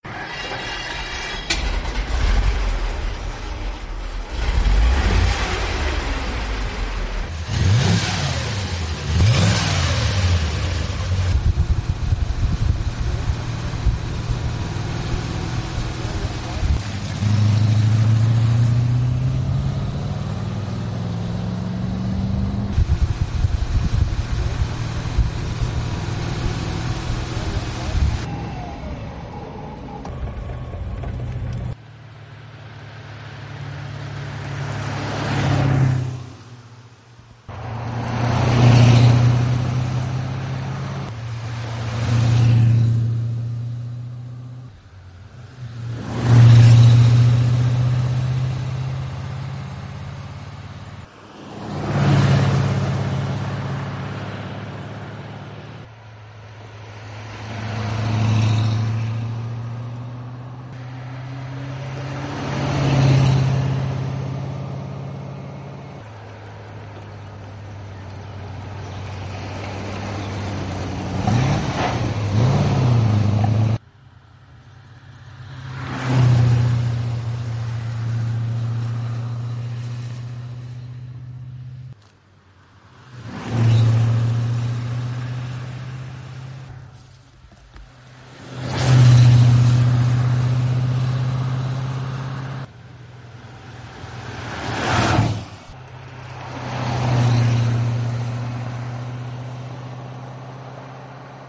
The Tatra was the world's first mass-produced streamlined vehicle. Equipped with the characteristic rear fin and an air-cooled V8 engine, it could reach speeds of up to 165 kilometres per hour.
0150_Motorengeraeusch.mp3